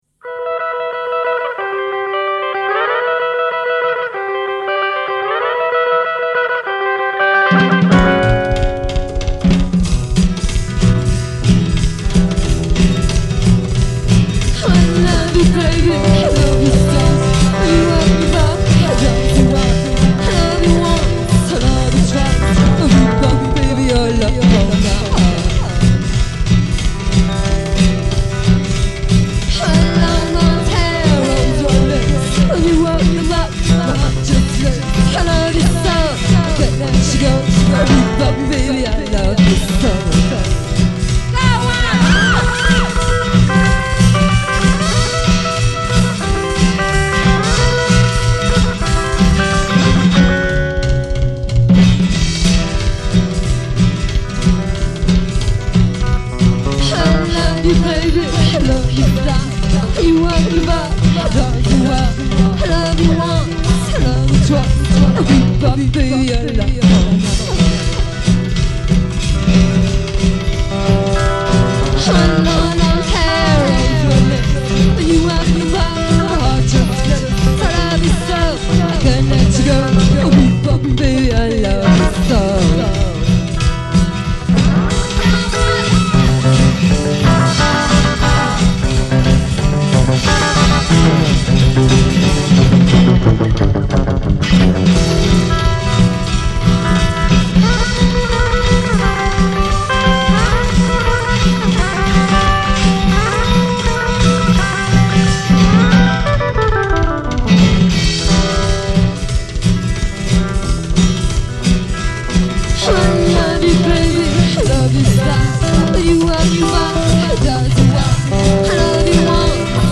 Frau singt in der Männerdomäne Rock ’n Roll.